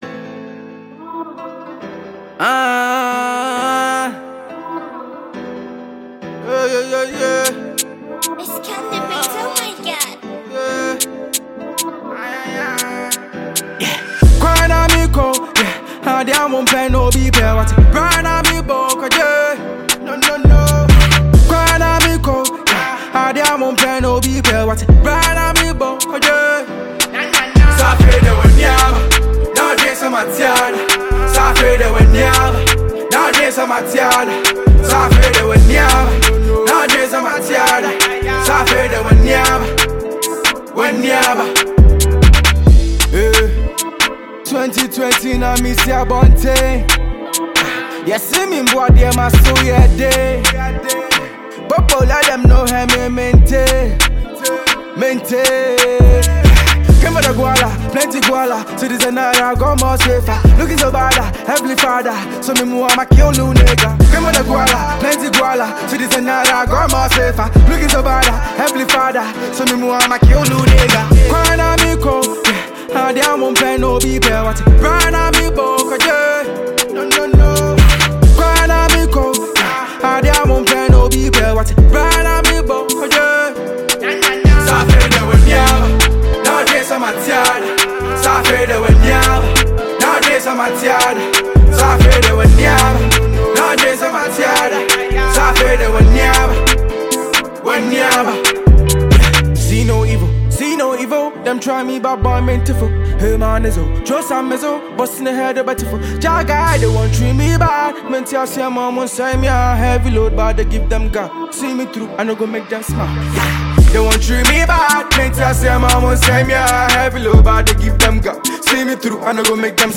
Prominent Ghanaian rapper